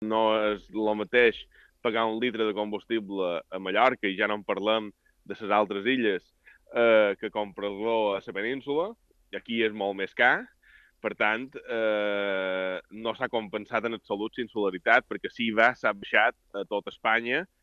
En una entrevista a l'Informatiu Matí d'IB3 Ràdio